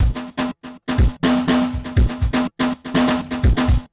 DrumLoop01.wav